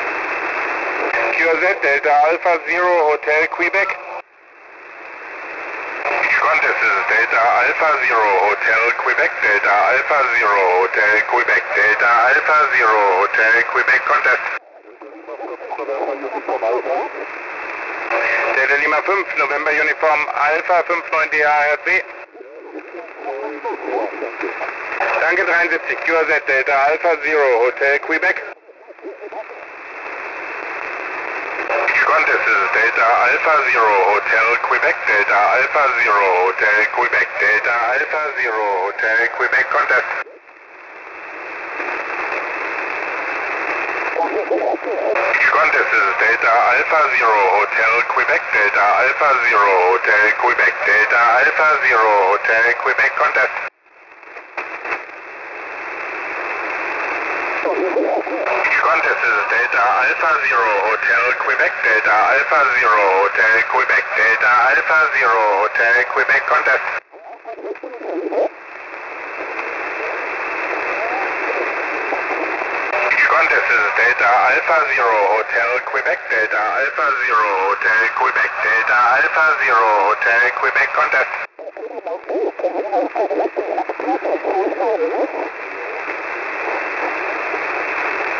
⇓ RX - u.a. FULDA ⇓
⇓ FULDA ⇓